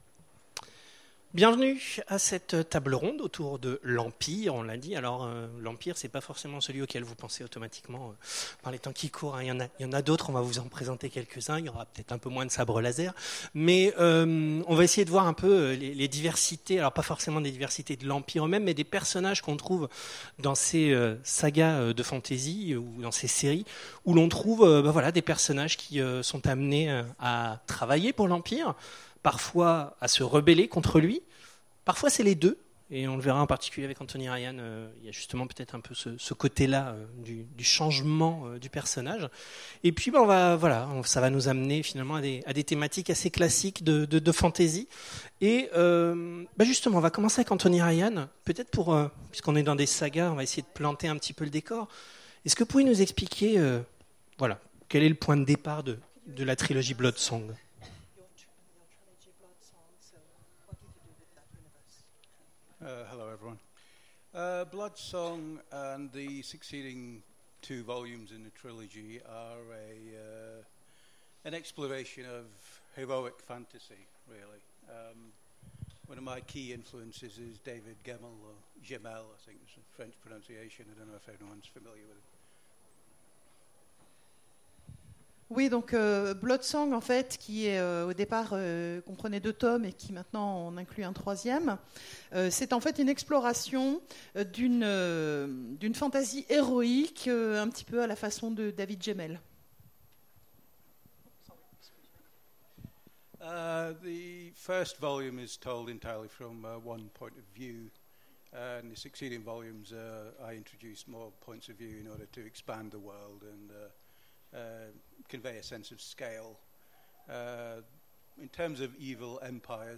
Imaginales 2016 : Conférence L’Empire…